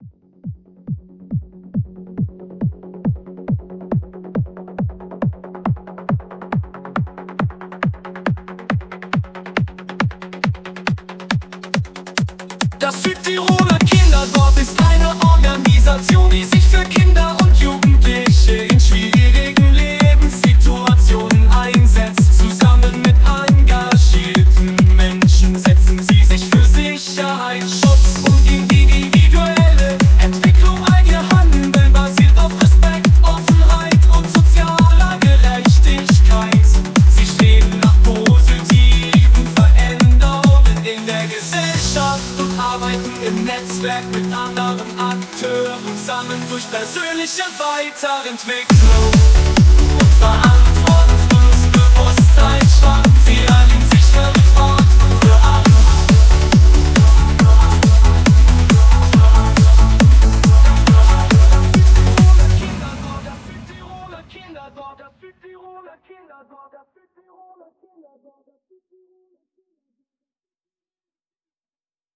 La nostra dichiarazione di missione è disponibile anch e sotto forma di canzone!